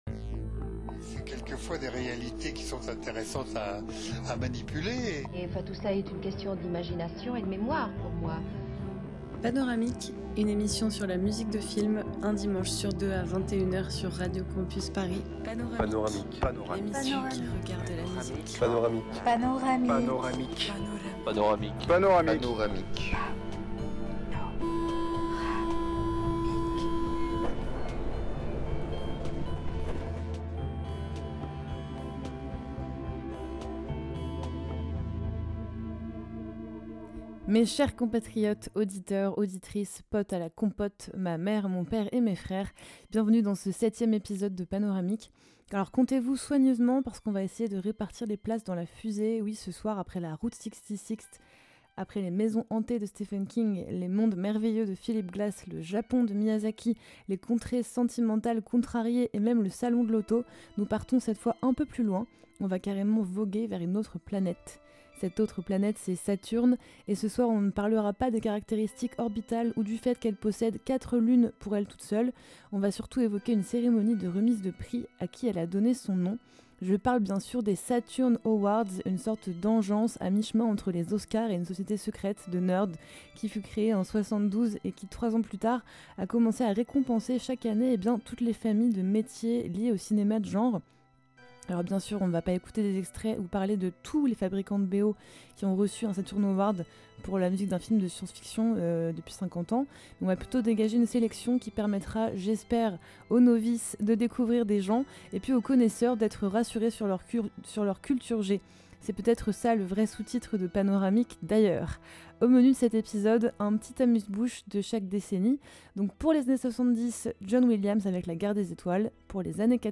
Mix
Éclectique